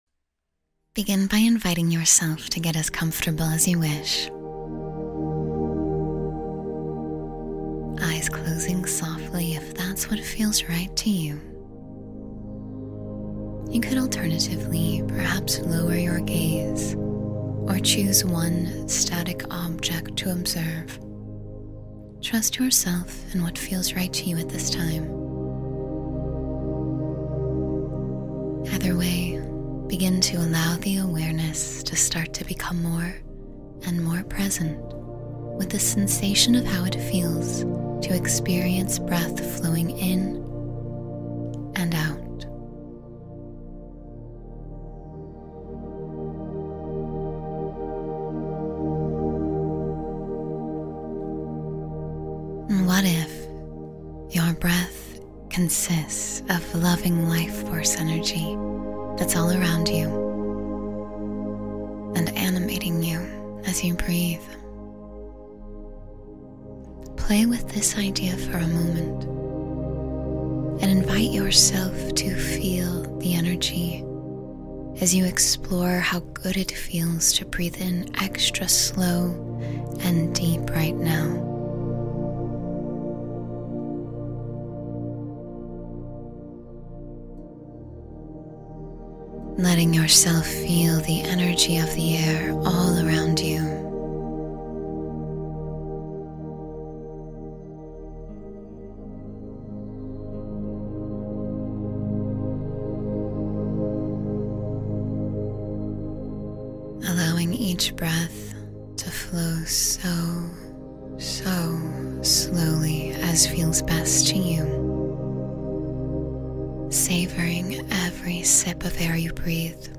You Are Worthy of Love and Peace — Meditation for Self-Worth and Compassion